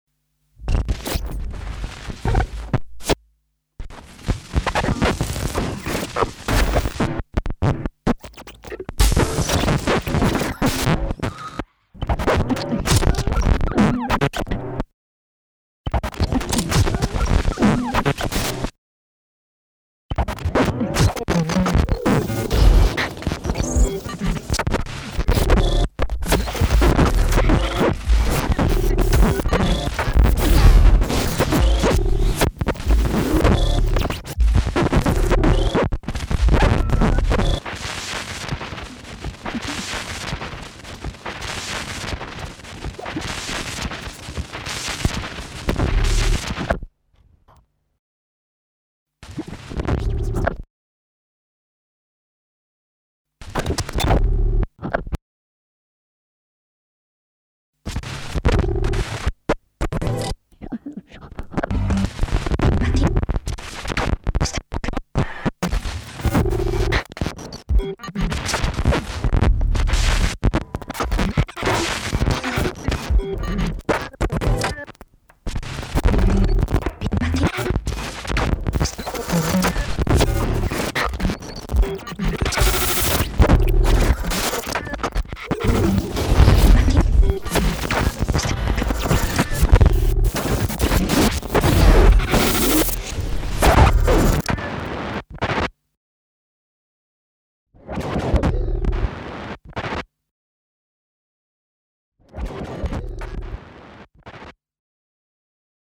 octophonic piece